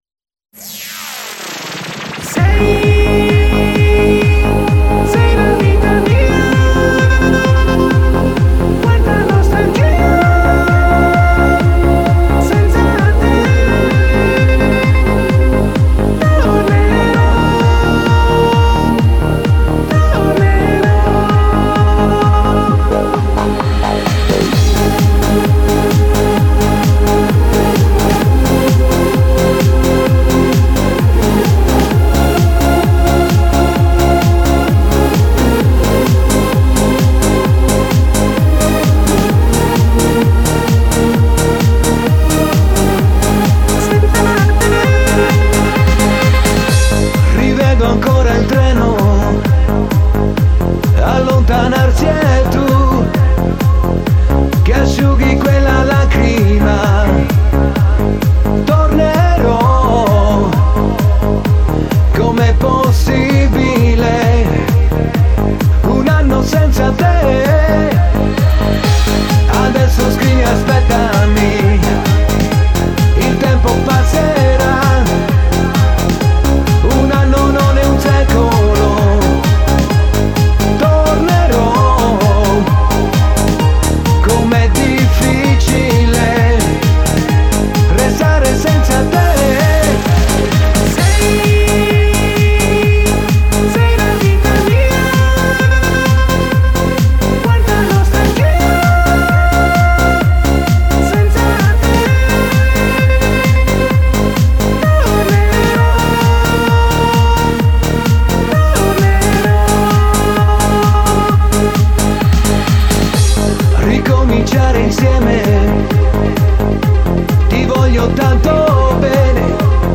club radio mix